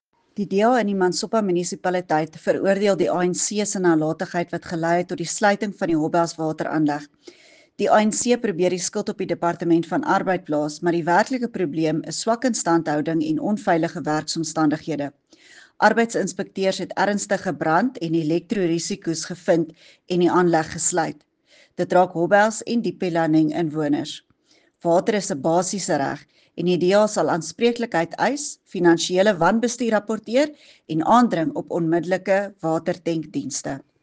Afrikaans soundbite by Dulandi Leech MPL